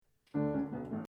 It is possible because Beethoven takes a little motive, the very first four notes taken from the beginning: